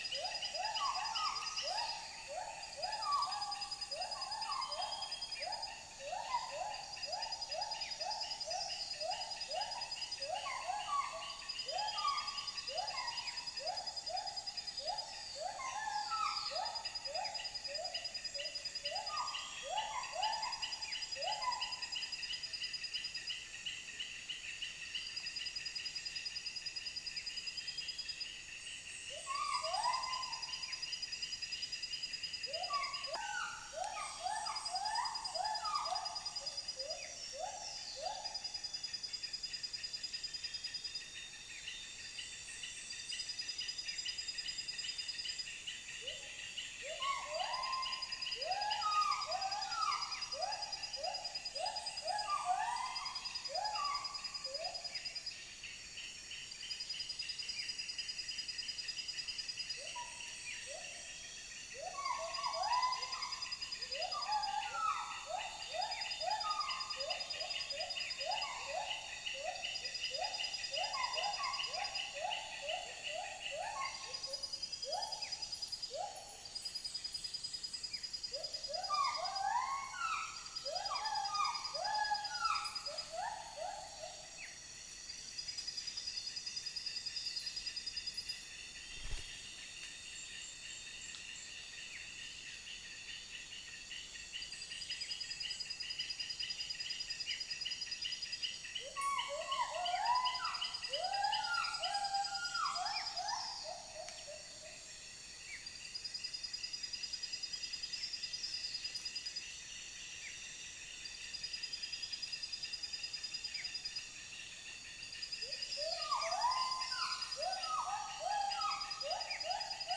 Stachyris maculata
Trichastoma malaccense
Aegithina viridissima
Chloropsis moluccensis